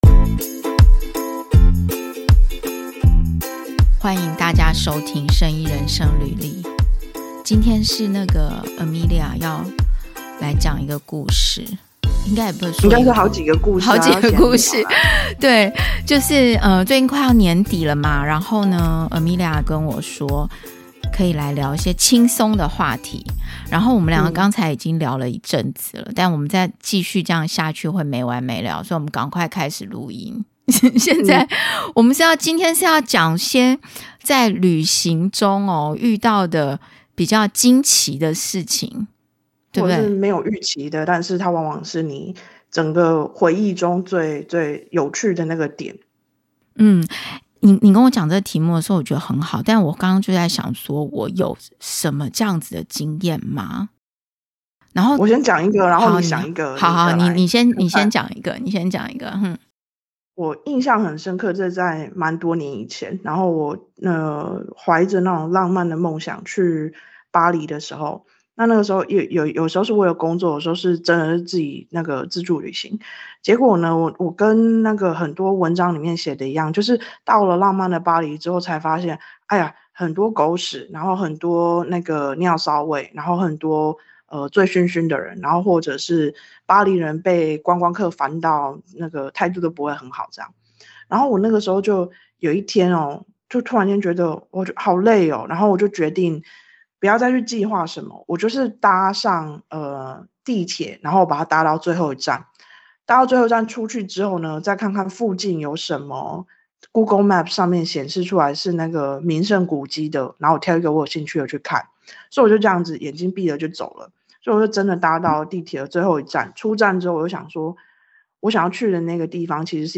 旅途中意想不到的事情，可能帶來驚奇，也可能是驚喜，回想起來，往往是最印象深刻的部分。這集我們是朋友聊天，分享旅途中的故事。